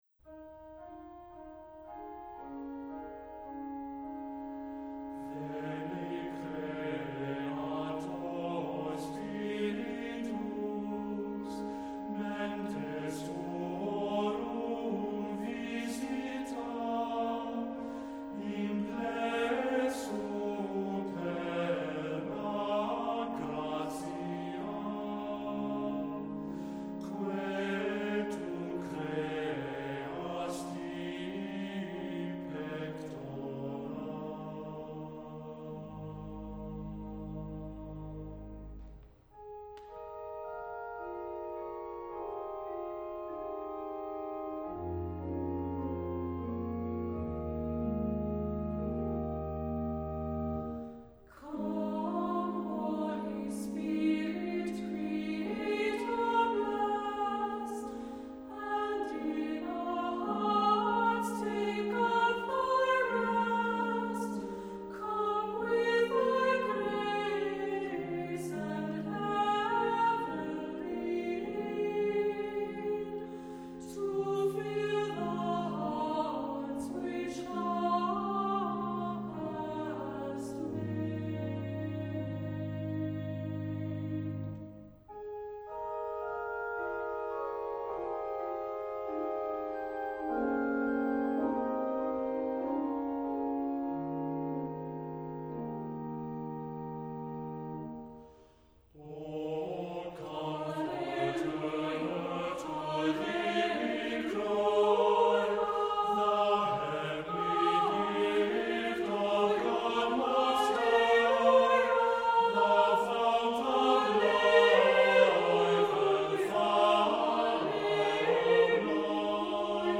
Accompaniment:      Organ
Music Category:      Christian